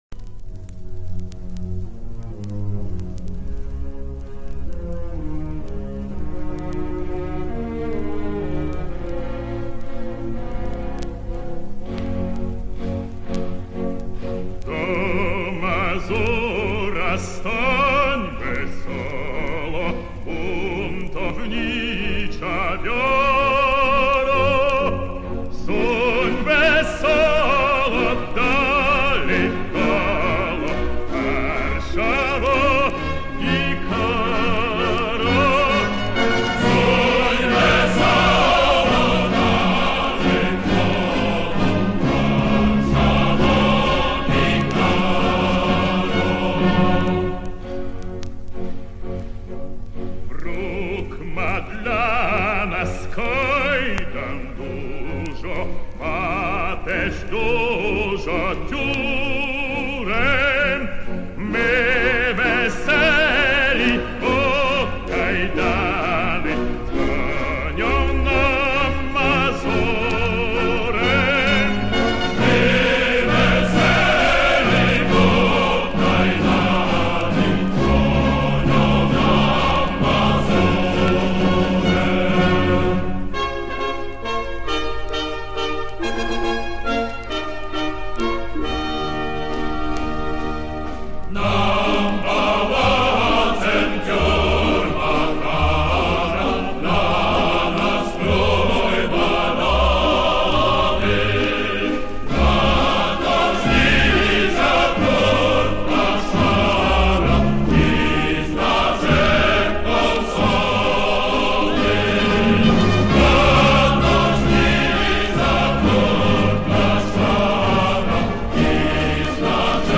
Исполняется три куплета из полутора десятков.